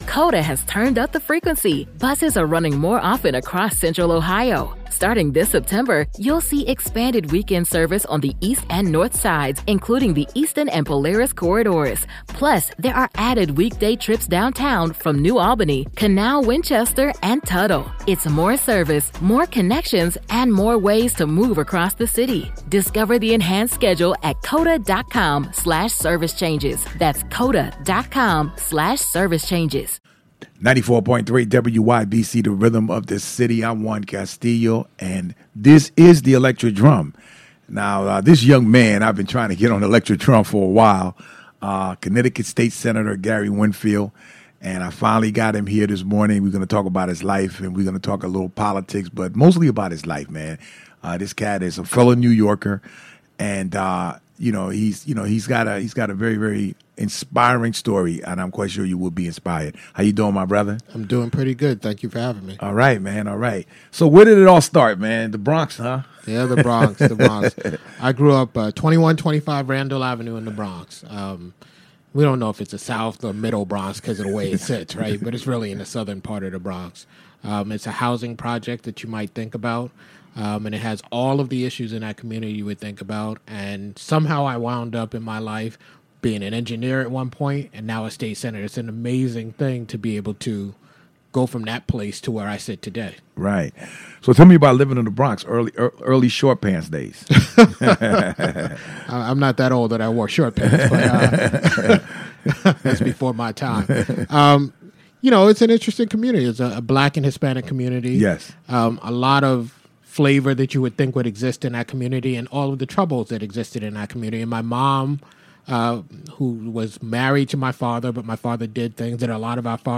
ELECTRIC DRUM INTERVIEW WITH GARY WINFIELD